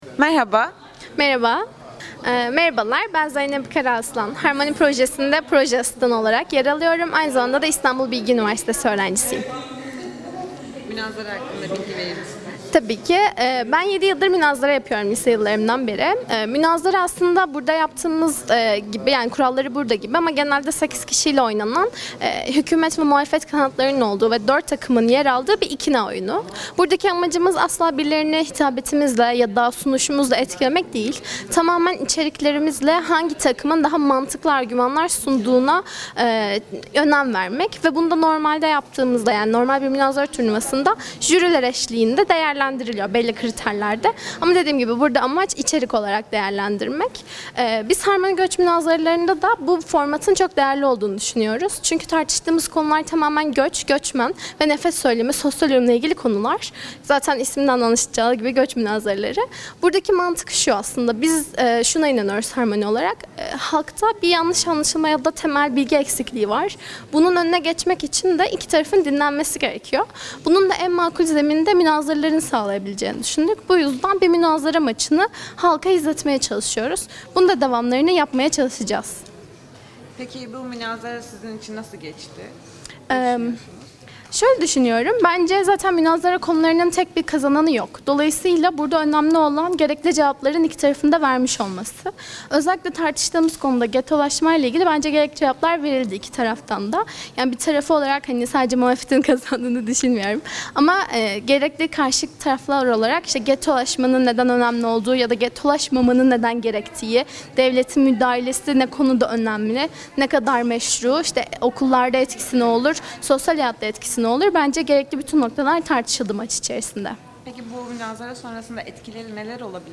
Göçmenlere İkamet Sınırı | Münazara | Sınır Etkisi
Sınır Etkisi ekibi olarak oradaydık ve gençler arasında gerçekleşen münazarayı sizlerle paylaşıp değerlendirelim.